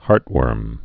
(härtwûrm)